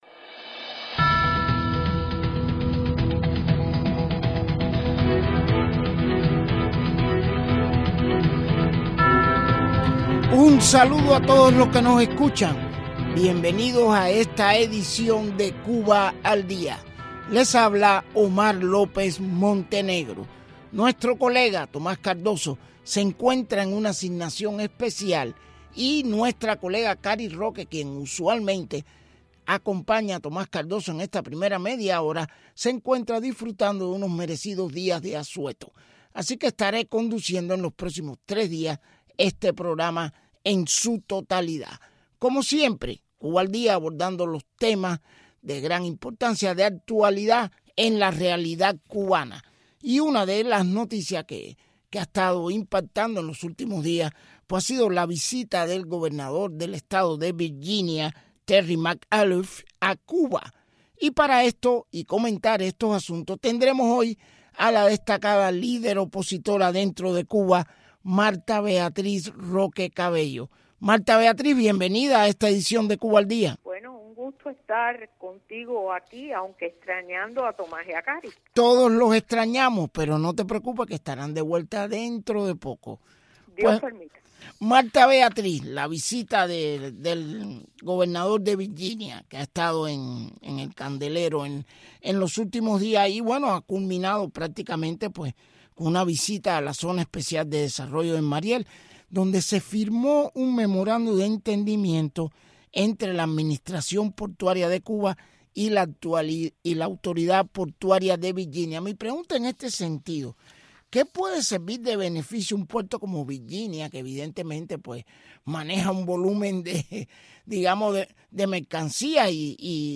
Entrevistas a Marta Beatriz Roque